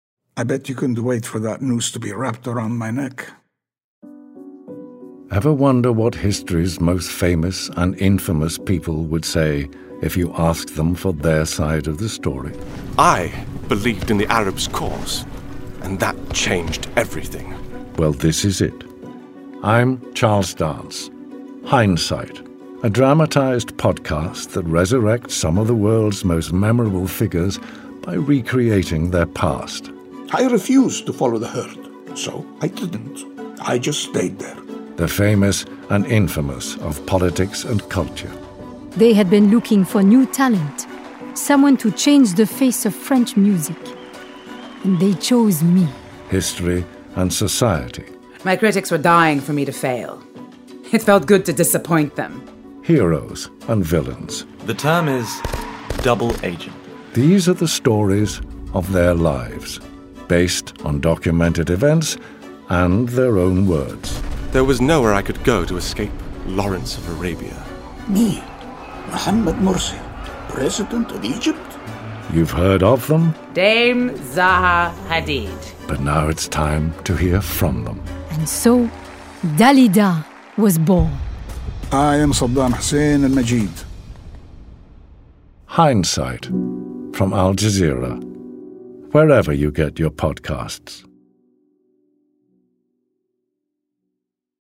In Hindsight, a new docudrama podcast from Al Jazeera, actor Charles Dance plays our narrator, navigating the lives of some of the world’s most memorable figures.
Hindsight-The-Trailer.mp3